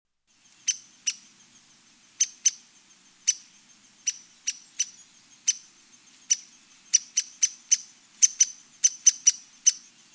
Downy Woodpecker
downy.mp3